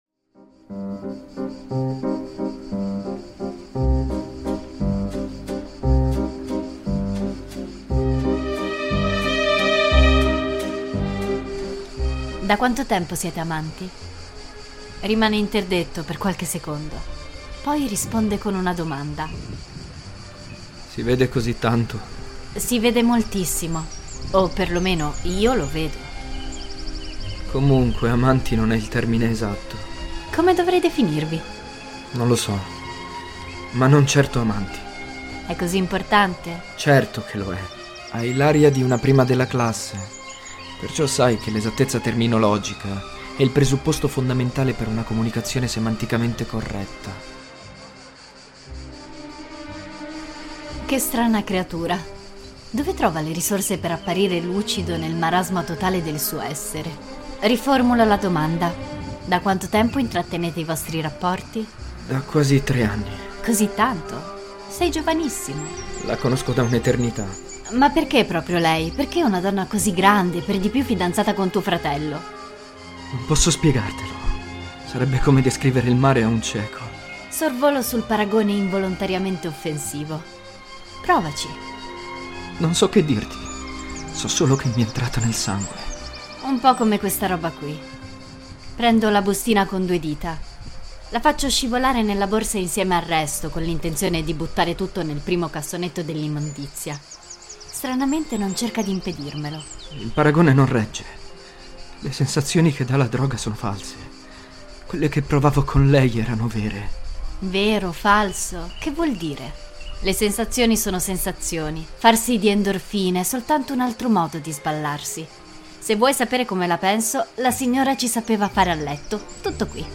3.17. Labirinti - Parte II (Vieni via con me...) - Emmanuel - The broken diary (Podcast Novel)